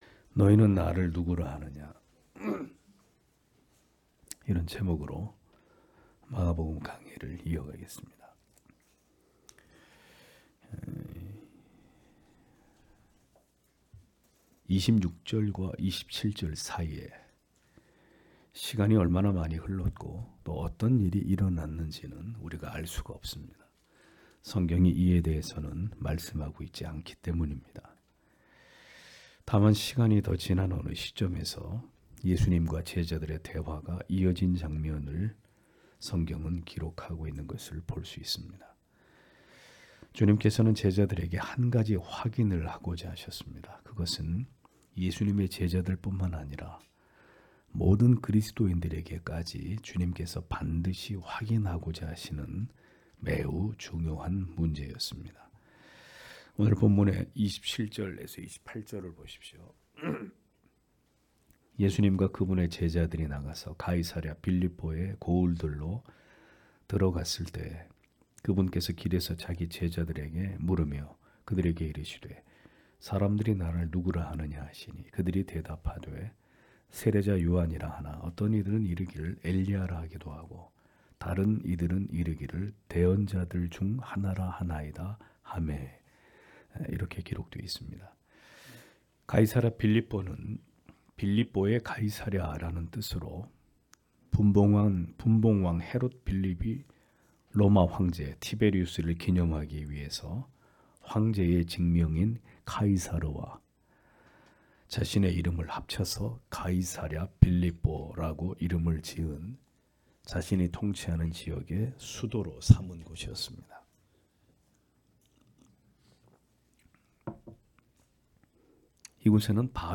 주일오전예배 - [마가복음 강해 32] 너희는 나를 누구라 하느냐 (막 8장 27-33절)